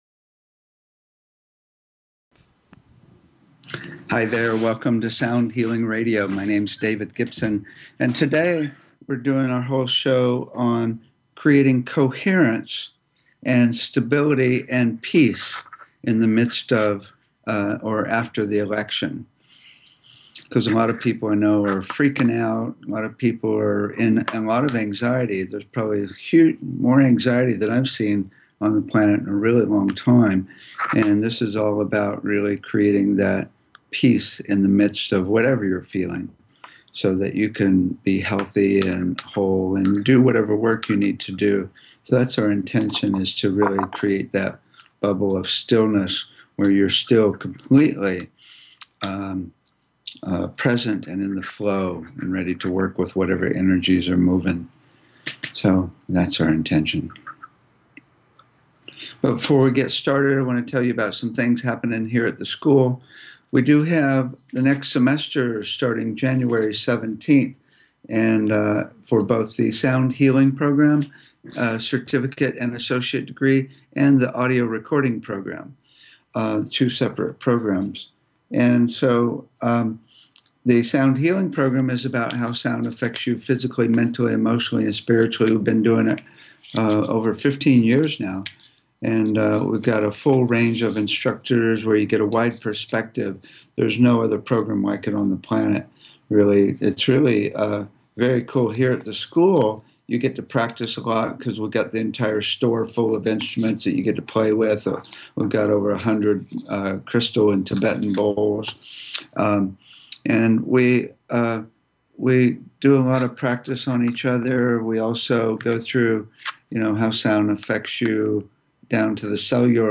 Talk Show Episode, Audio Podcast, Sound Healing and Sound of Enlightenment on , show guests , about Sound of Enlightenment, categorized as Health & Lifestyle,Sound Healing,Kids & Family,Music,Philosophy,Psychology,Self Help,Spiritual